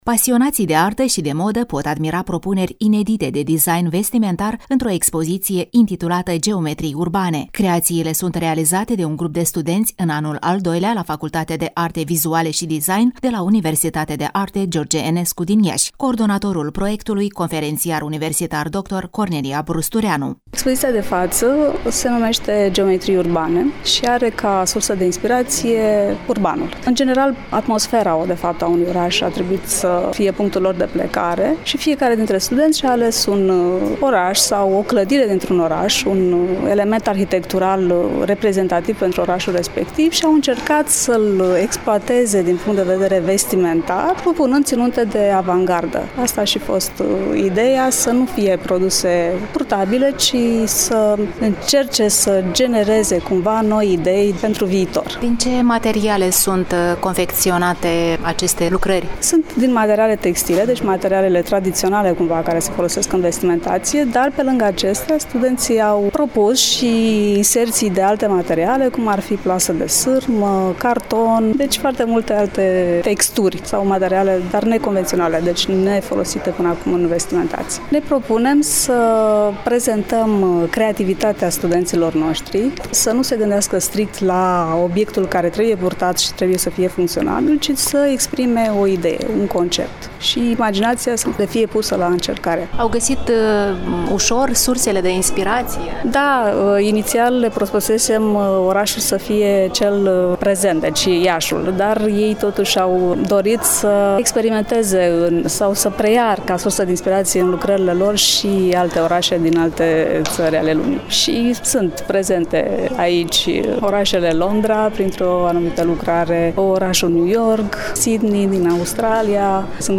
Prima pagină » Rubrici » Reportaj cultural » Expoziţiile lunii mai la Galeria de Artă Grand Hotel Traian